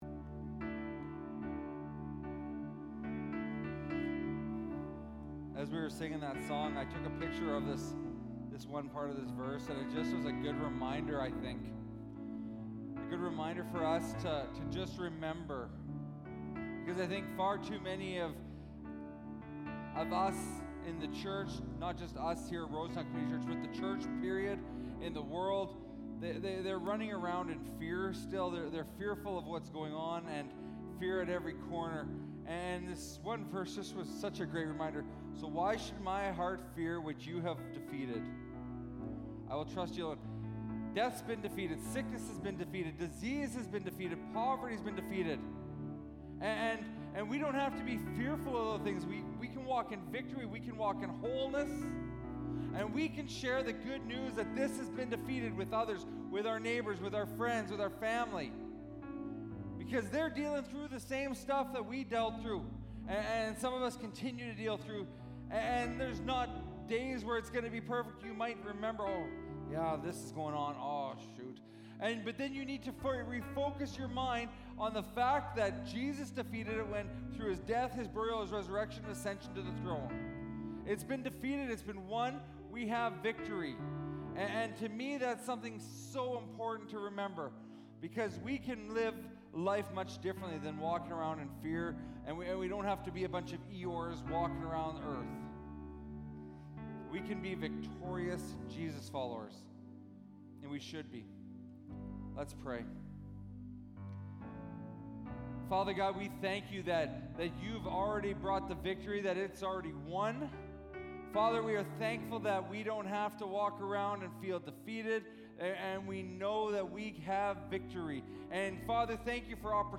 Sermons | Rosetown Community Church